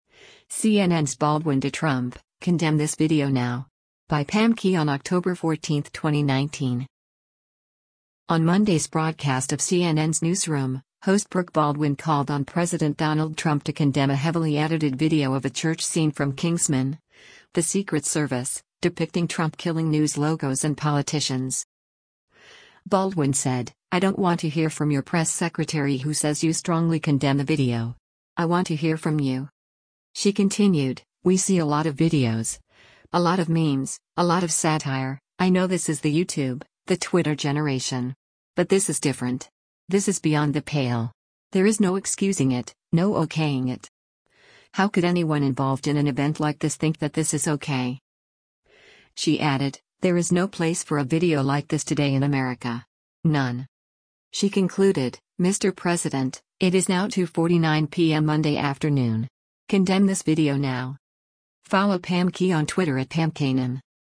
On Monday’s broadcast of CNN’s “Newsroom,” host Brooke Baldwin called on President Donald Trump to condemn a heavily edited video of a church scene from “Kingsman: The Secret Service,” depicting Trump killing news logos and politicians.